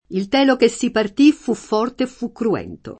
telo [ t $ lo ] s. m. («dardo»)